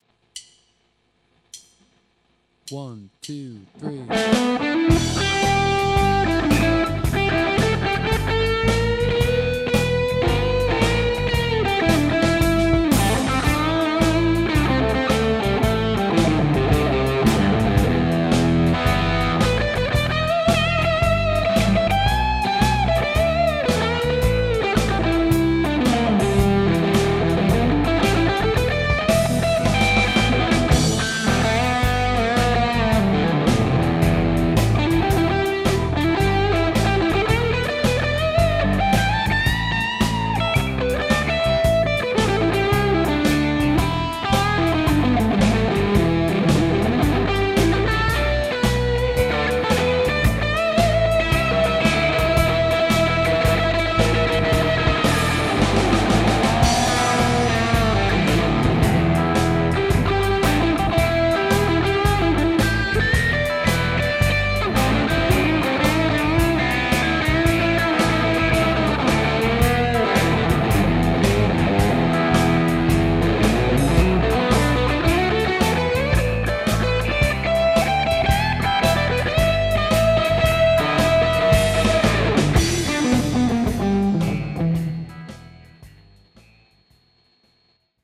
Tarjolla vahvasti vihersipulin makuista taustaa E:ssä:
- soita soolosi annetun taustan päälle